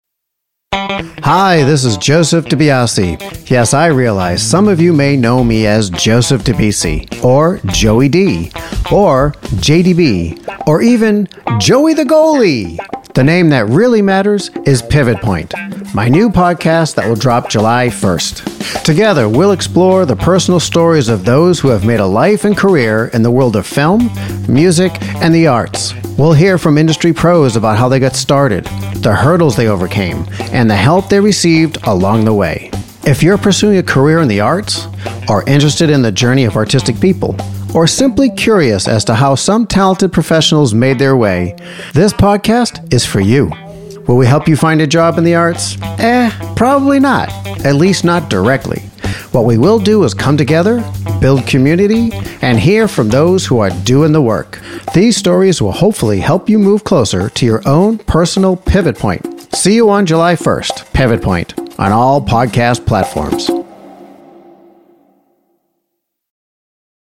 Genres: Arts, Film Interviews, Performing Arts, TV & Film
Trailer: